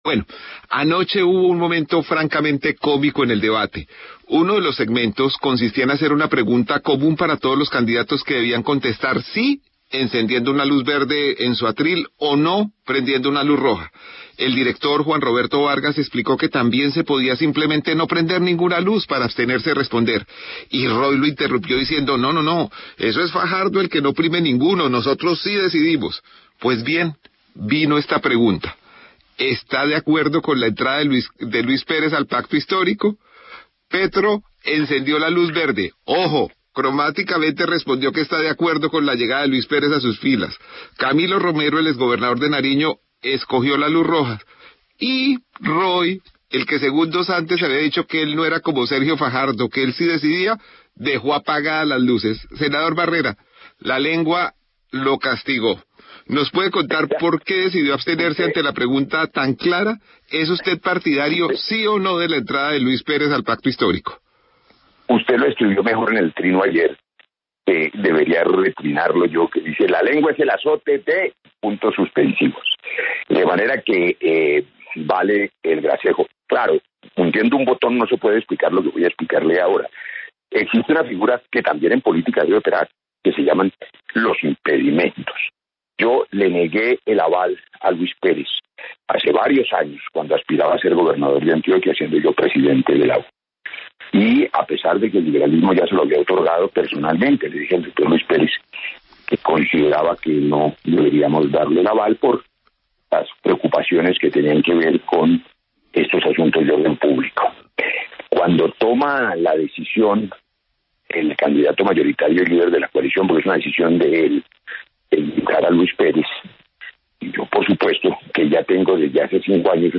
En El Reporte Coronell, el senador Roy Barreras conversó sobre el debate realizado entre candidatos de la coalición.